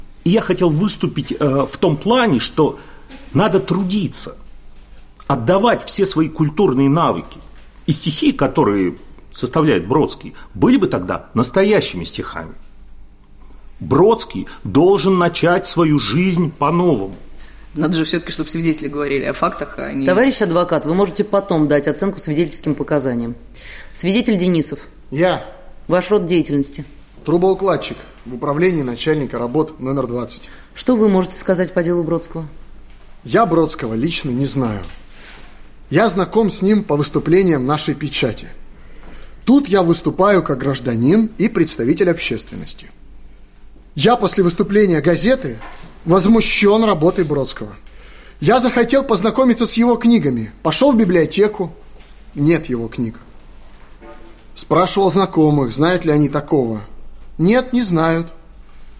Аудиокнига Бродский.
Aудиокнига Бродский. Суд Автор Савва Савельев Читает аудиокнигу Актерский коллектив.